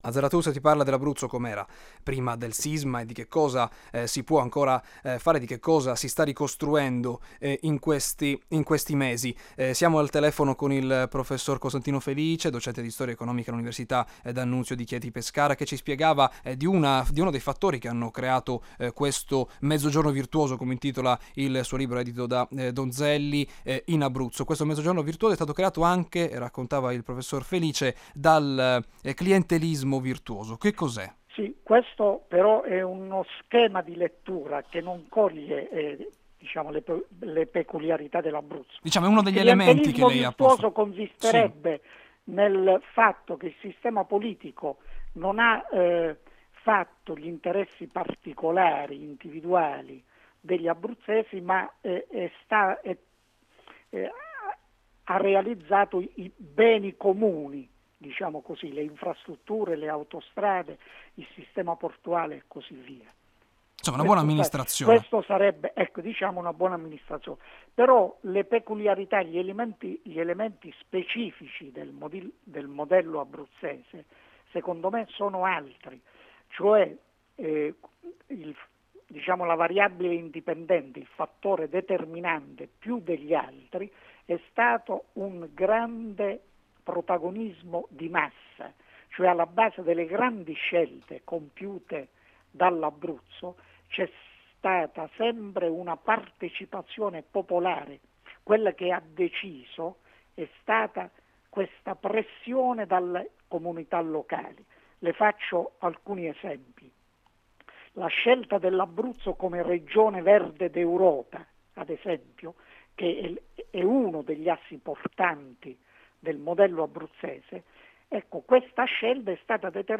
Ascolta la puntata di Zarathustra andata in onda sabato 19 settembre, alle 18,05, su Radio Italia anni '60 - Emilia Romagna.